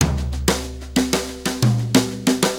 Brushes Fill 69-09.wav